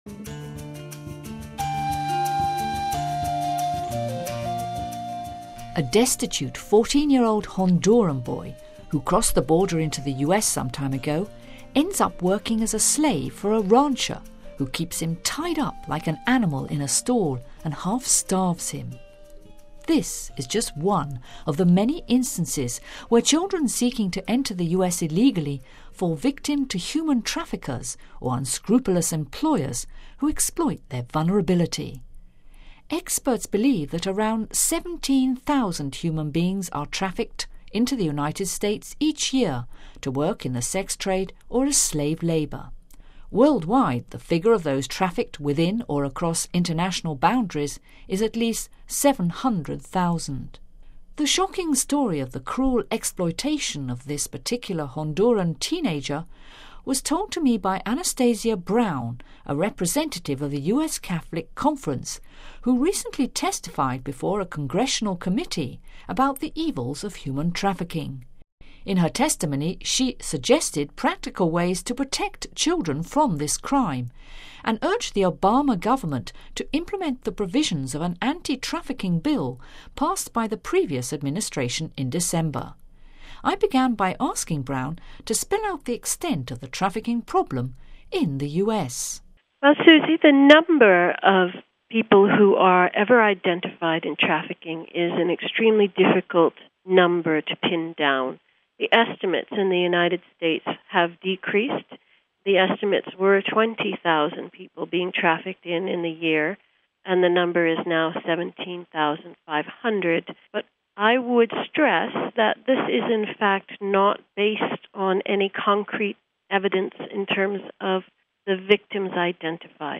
A representative of the U.S. Catholic Conference talks of the evil of human trafficking and how one 14 year old Honduran boy ended up working as a slave for a U.S. rancher who kept him tied up like an animal......